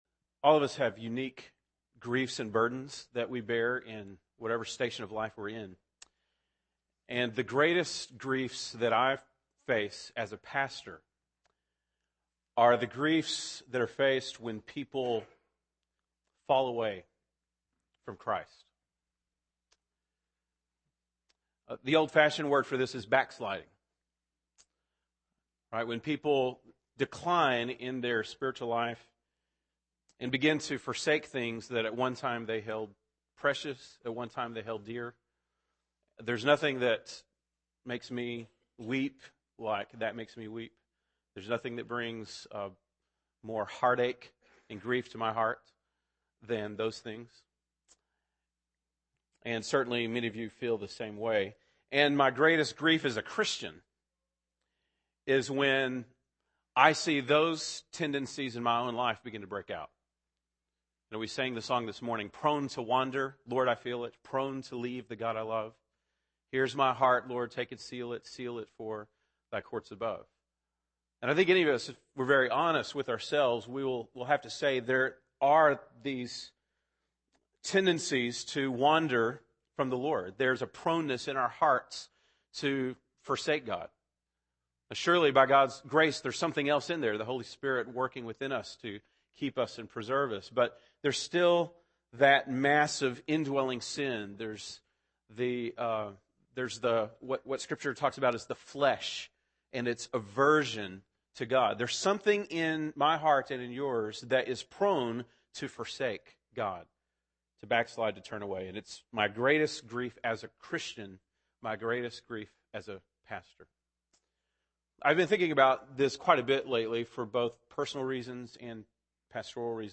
March 27, 2011 (Sunday Morning)